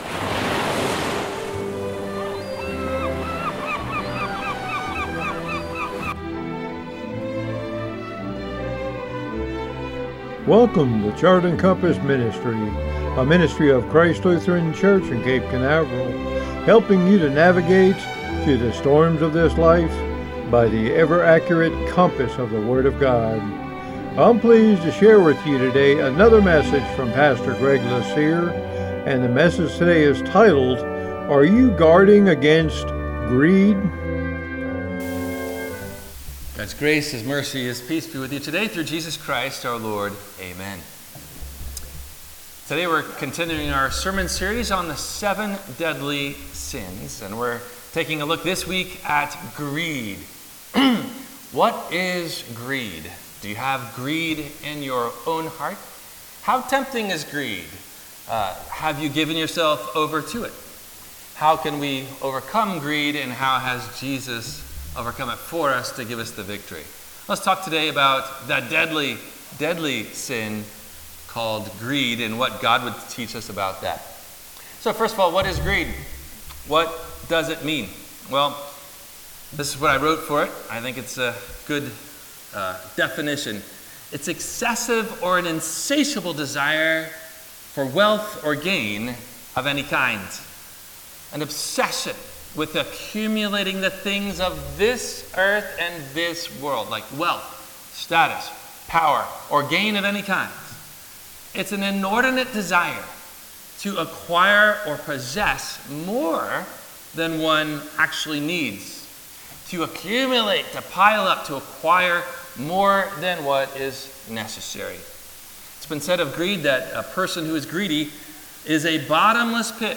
Are You Guarding Against…Greed? – WMIE Radio Sermon – March 31 2025